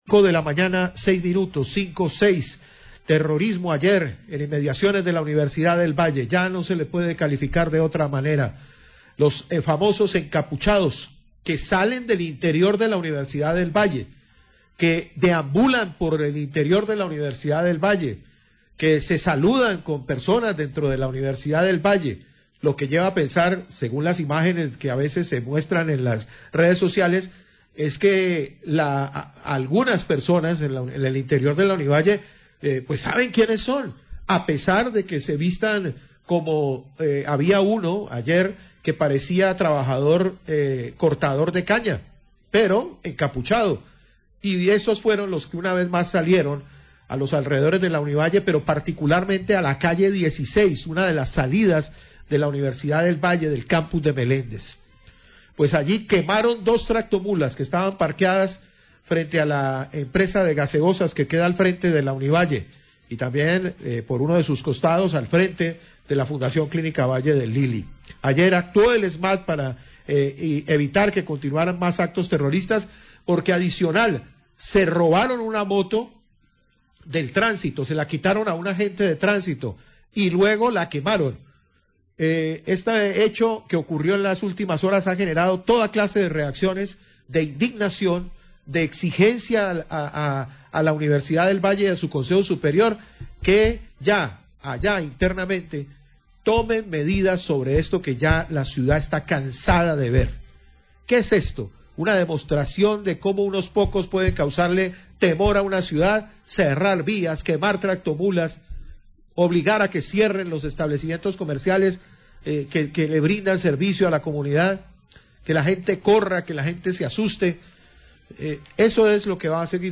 Radio
El comandante de la Policía de Cali, Henry Bello hace un resumen de los eventos. El secretario de seguridad, Jairo García, ofrece recompensa por información que de con el paradero de los encapuchados implicados.